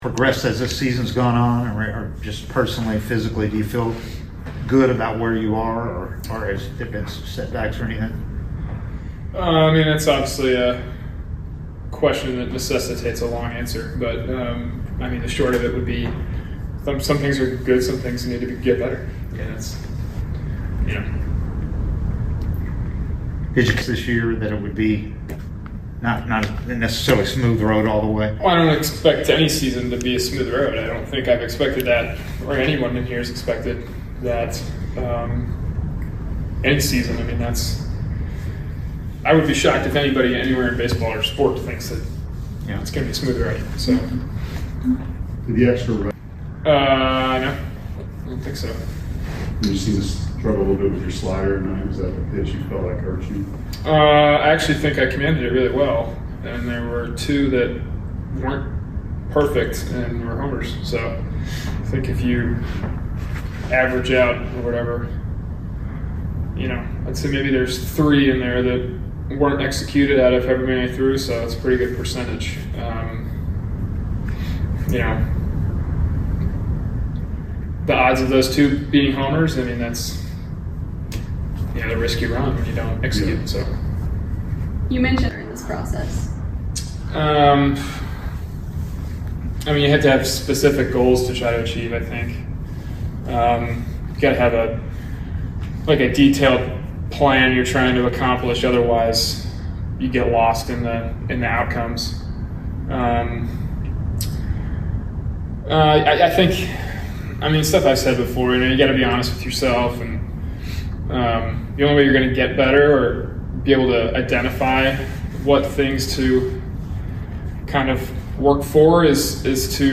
Atlanta Braves Pitcher Spencer Strider Postgame Interview after losing to the Milwaukee Brewers at Truist Park.